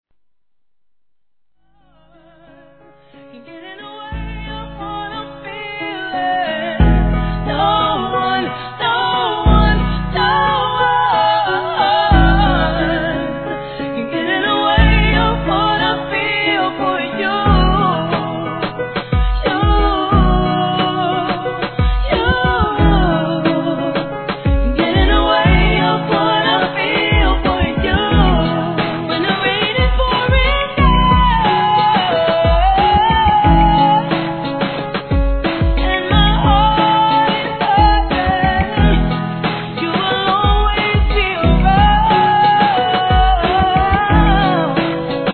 HIP HOP/R&B
極上ピアノREMIX